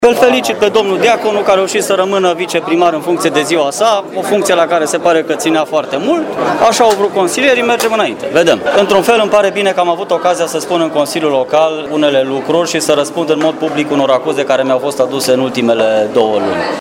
La rândul său, consilierul Bogdan Herzog, care a fost propus pentru funcţia de viceprimar, l-a felicitat acid pe Dan Diaconu pentru păstrarea funcţiei: